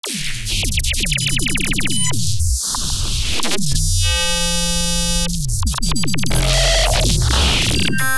OTT Artifact 10.wav